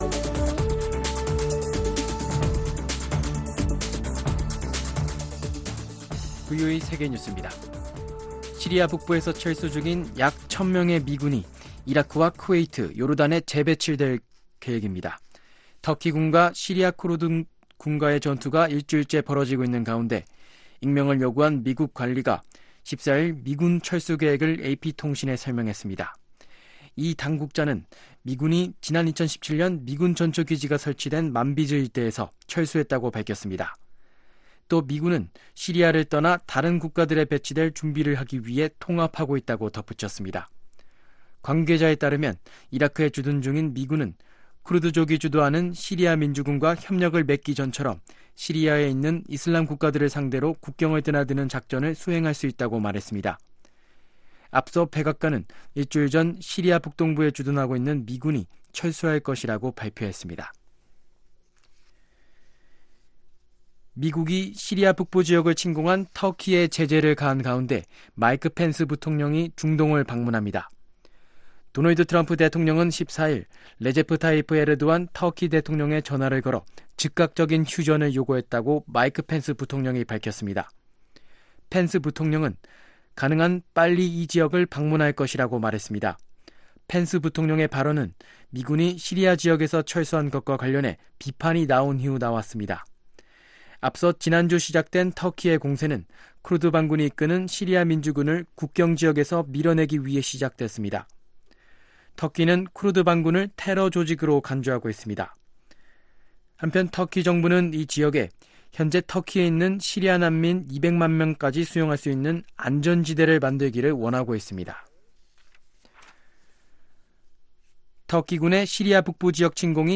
VOA 한국어 아침 뉴스 프로그램 '워싱턴 뉴스 광장' 2019년 10월 16일 방송입니다. 유엔총회 제 1위원회에서 북한의 탄도미사일 발사를 규탄하고 비핵화를 촉구하는 각국의 목소리가 이어지고 있습니다.